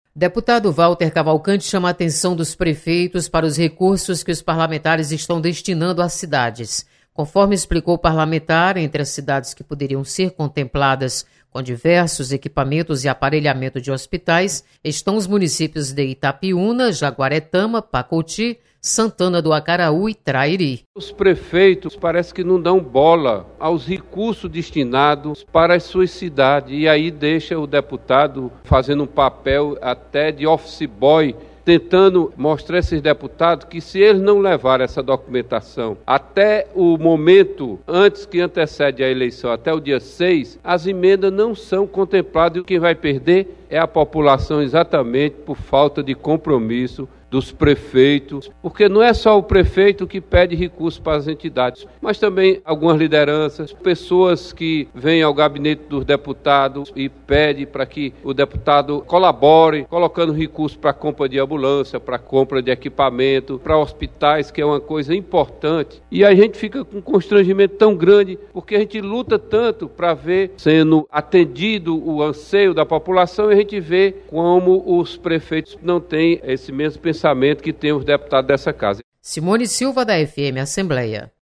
Deputado Walter Cavalcante esclarece sobre destino de emendas parlamentares. Repórter